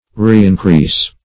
Reincrease \Re`in*crease"\ (-kr?s"), v. t. To increase again.